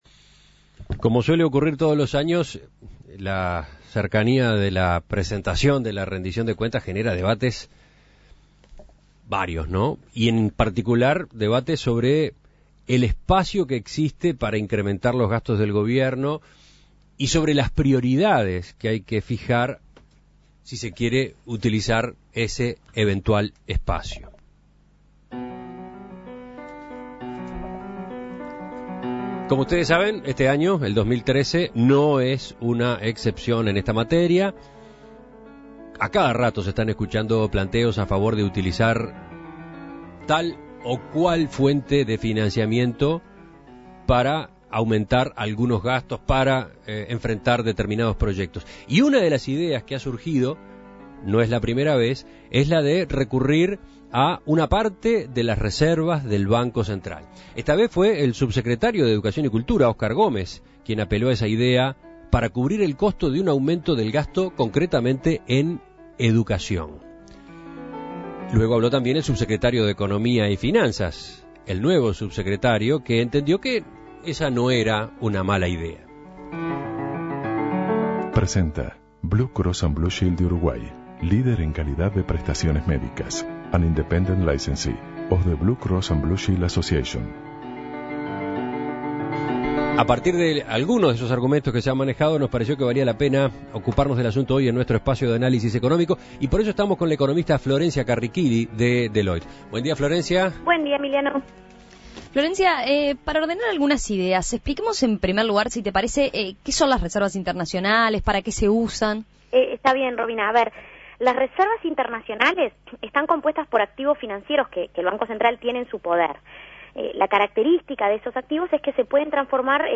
Análisis Económico Rendición de Cuentas: ¿Qué espacio tiene el Gobierno para aumentar el gasto?